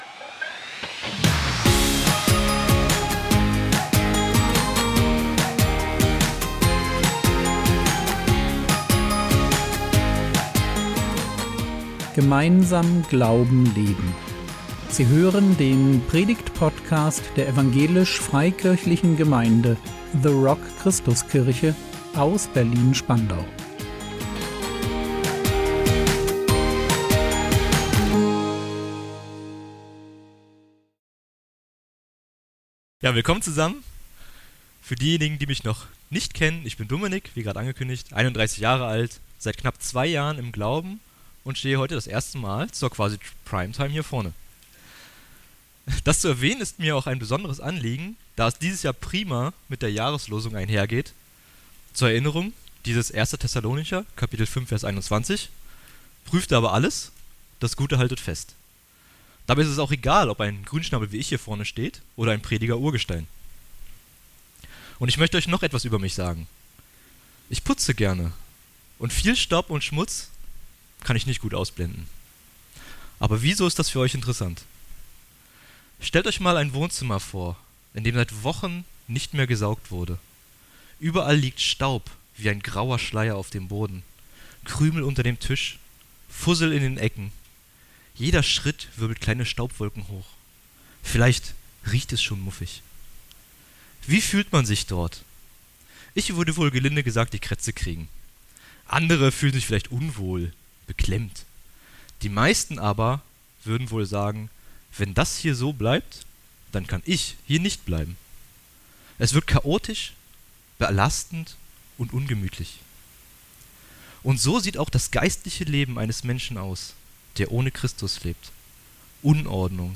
Zeit für einen geistlichen Hausputz | 12.10.2025 ~ Predigt Podcast der EFG The Rock Christuskirche Berlin Podcast